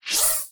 Scifi Screen UI 6.wav